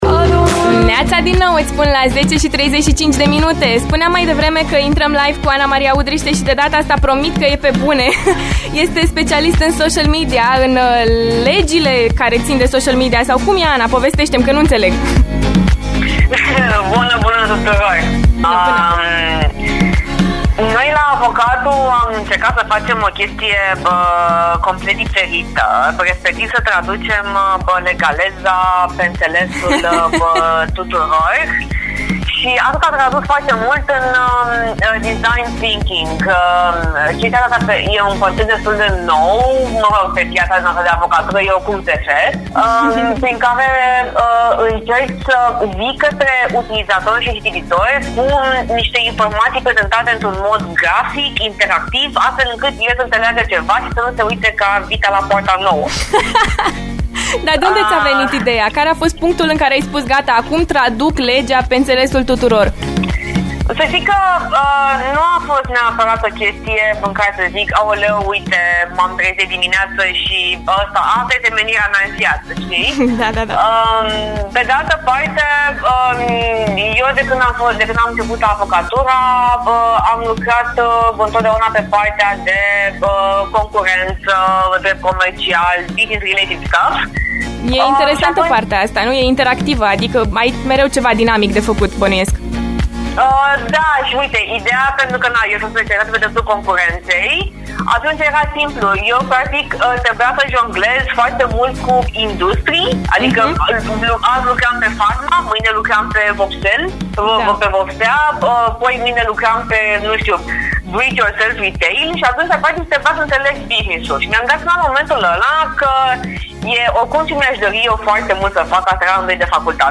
LIVE la After Morning